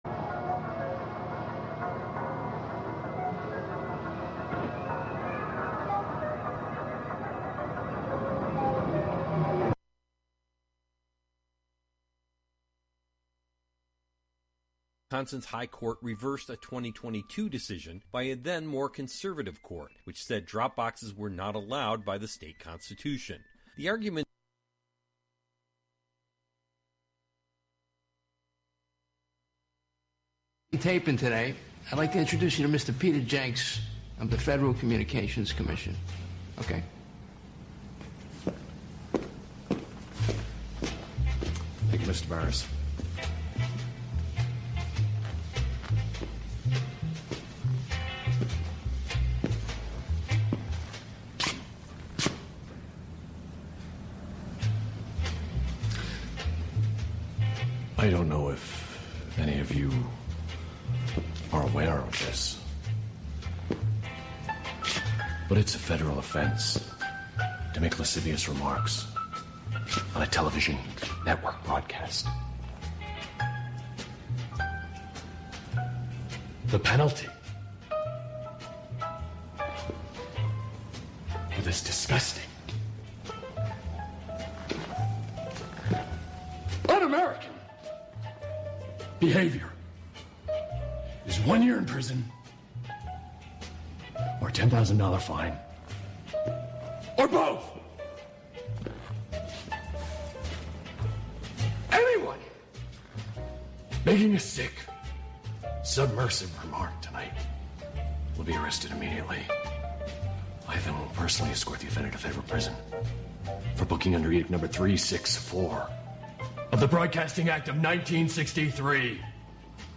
Well, if you are listening LIVE, then you may very well get to ask that question--as a call-in to the show.